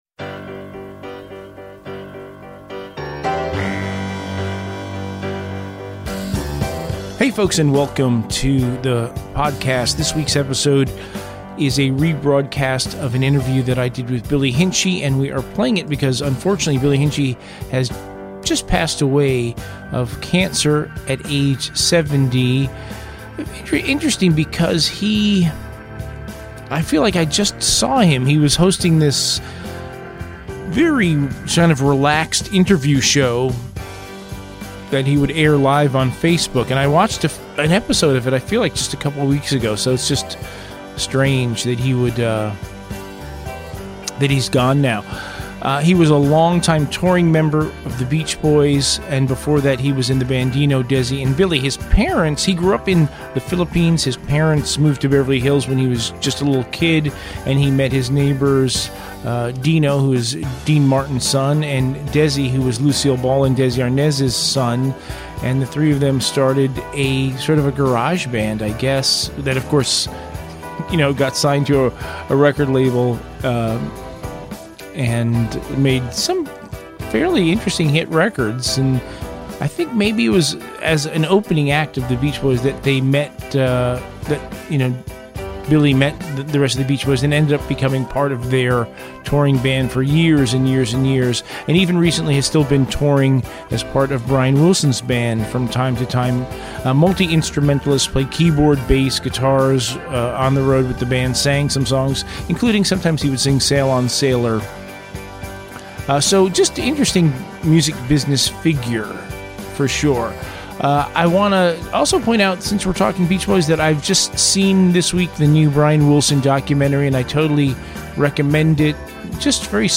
Billy Hinsche - "Interview"